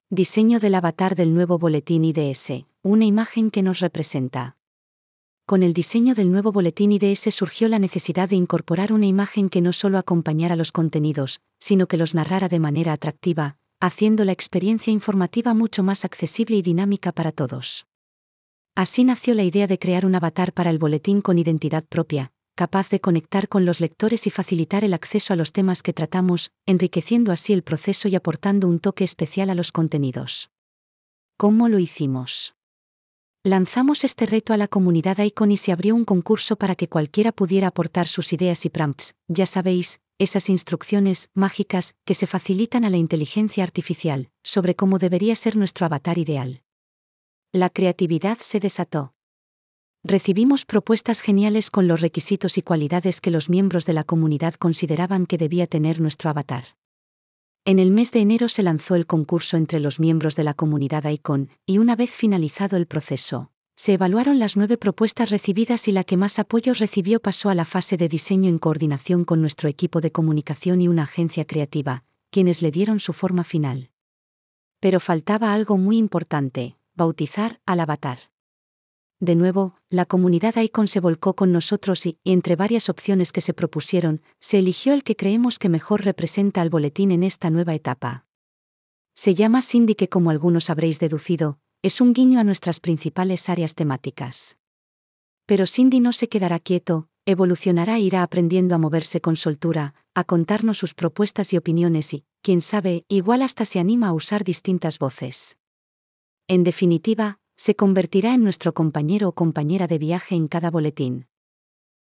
Para dar respuesta a esta necesidad, se abrió un proceso participativo en el marco de las iniciativas de AICON y ONCE Innova, para definir el avatar que acompañará las locuciones generadas por inteligencia artificial en el Boletín IDS.